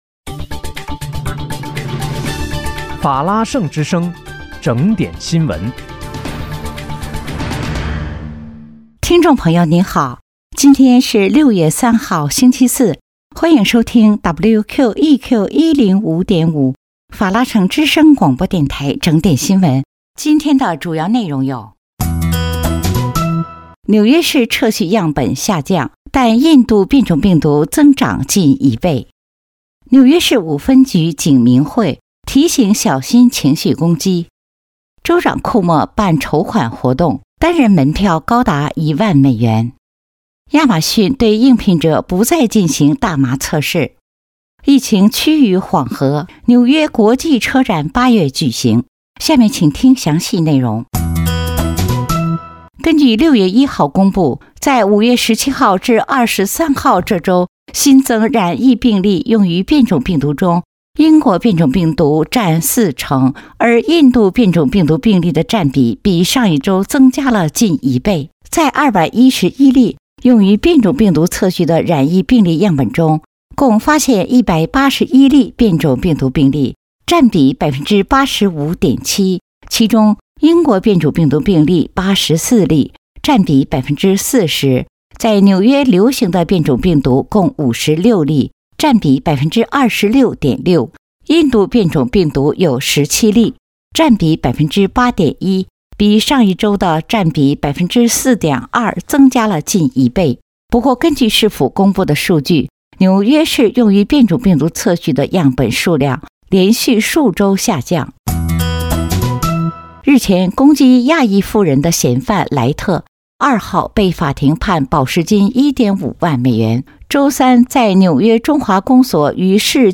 6月3日（星期四）纽约整点新闻